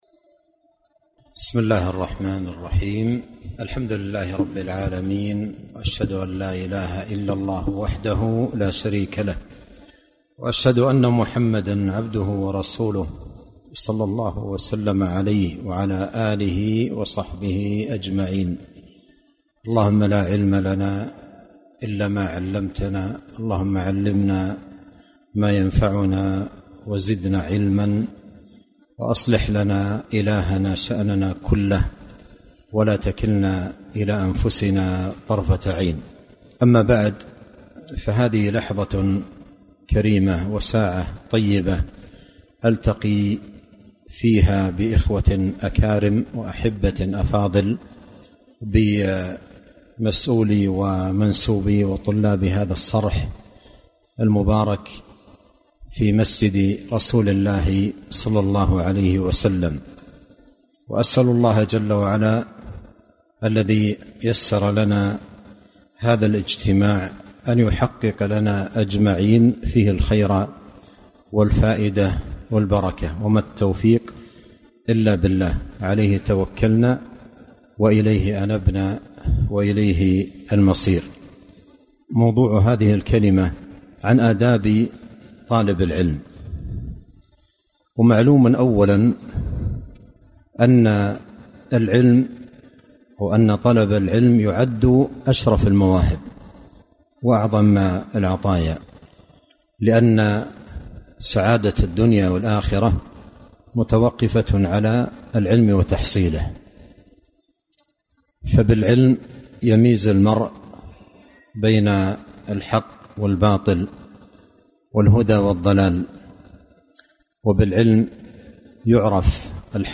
كلمة - آداب طالب العلم